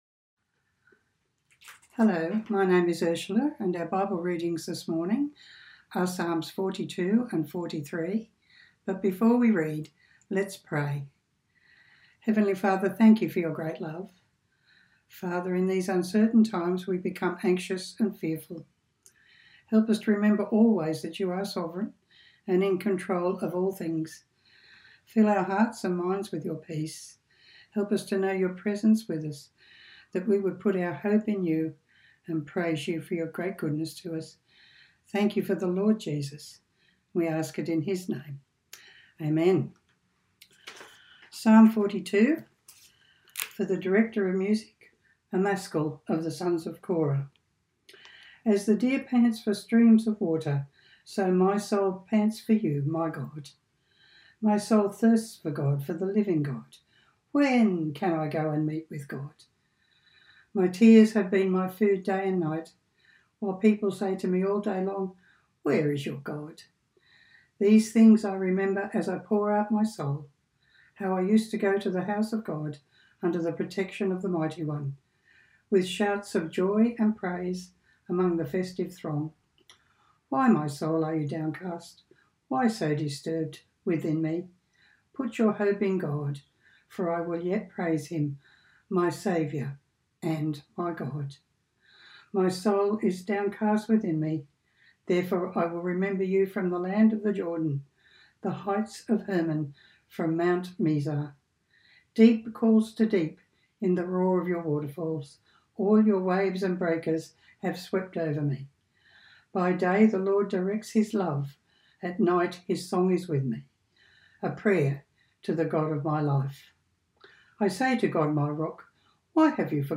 Bible Reading and Talk